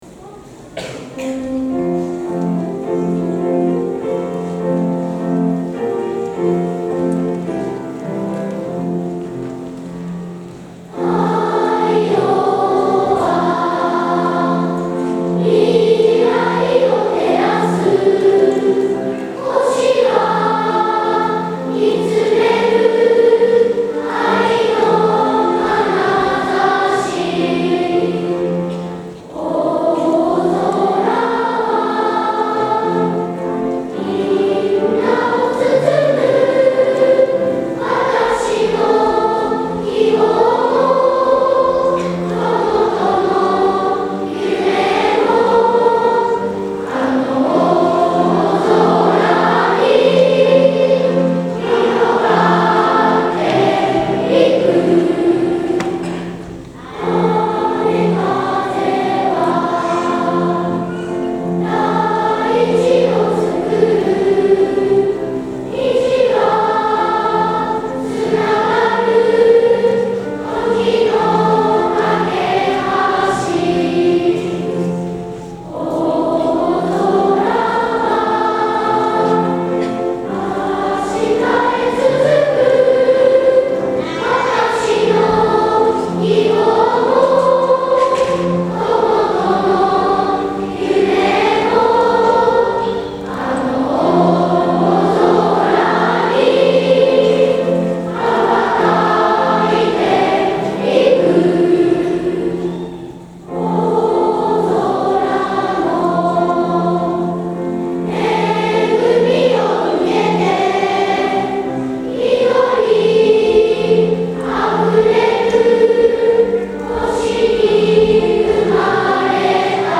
校歌」全校2部合唱です。
みんなが大切にしている校歌を歌い、会場のみんなで歌声を合わせます♪